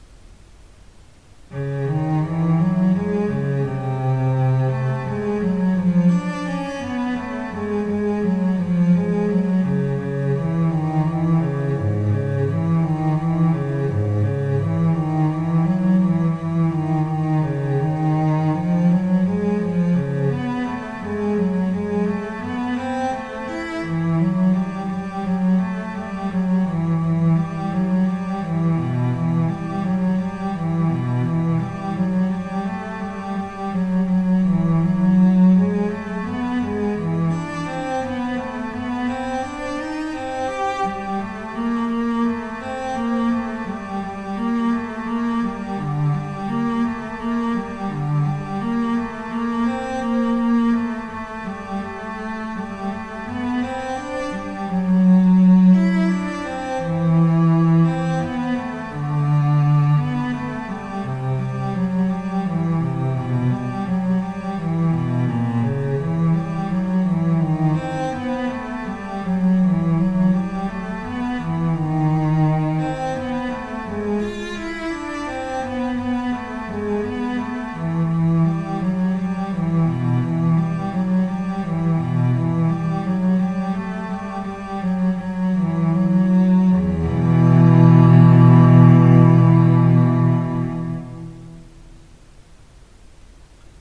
マイクロホンの位置は、フロントスピーカーから1.8ｍ離した高さ1mの場所。